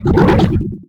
combat / creatures / alien / he / attack1.ogg
attack1.ogg